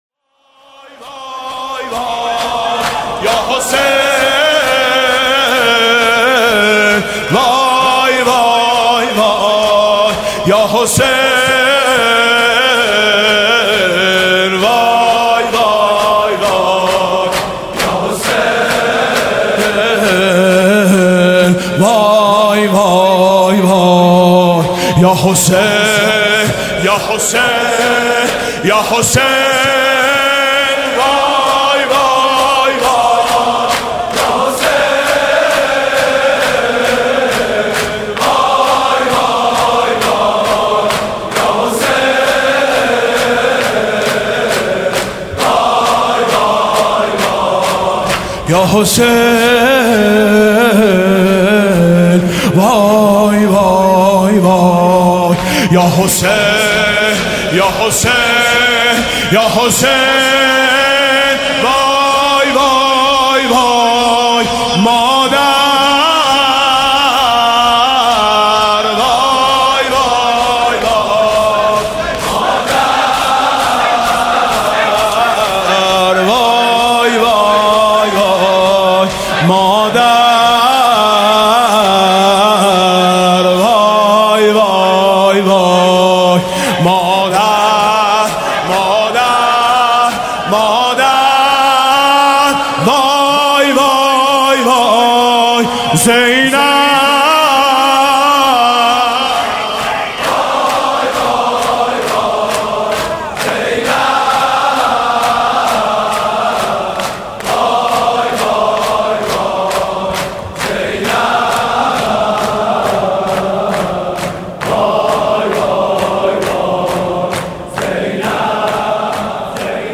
حاج میثم مطیعی/شب دوم محرم الحرام 95/هیئت میثاق با شهدا
نوا:وای یا حسین